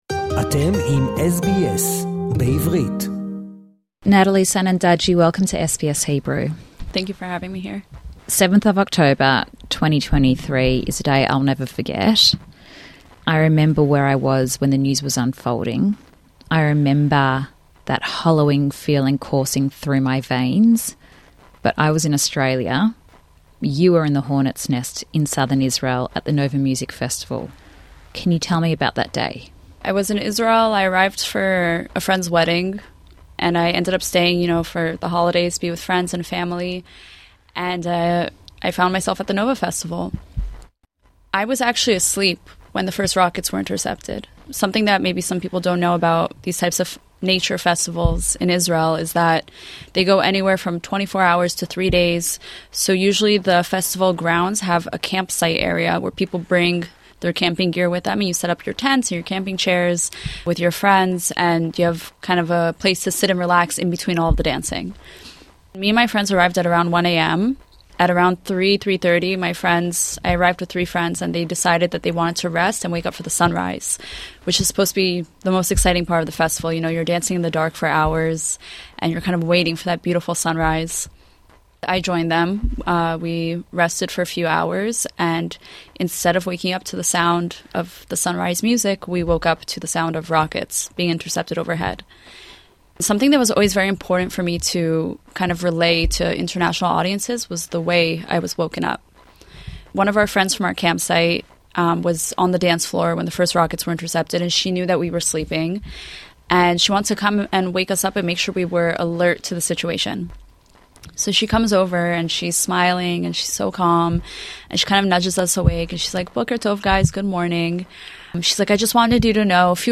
In a moving conversation with SBS Hebrew